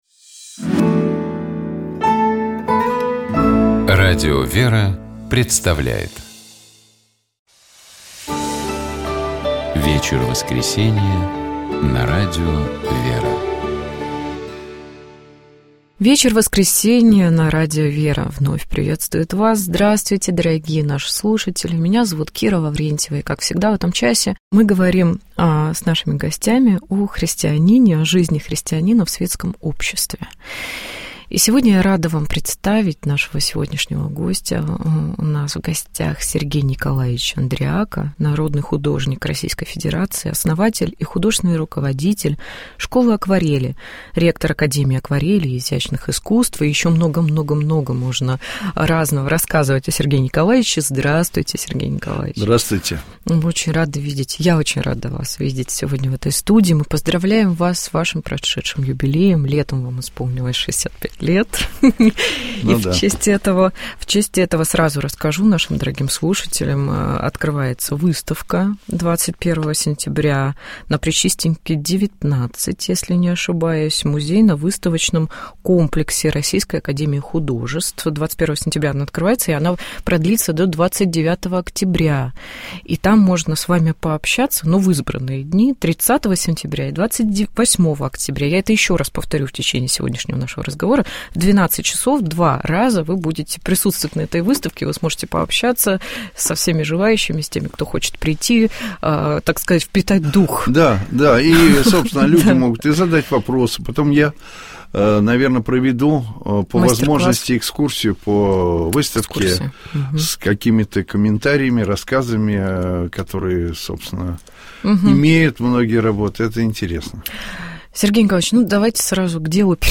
У нас в гостях был народный художник России, ректор Академии акварели и изящных искусств Сергей Андрияка.